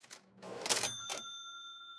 cash-register.mp3